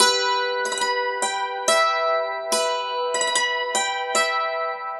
Dulcimer02in6_72_G.wav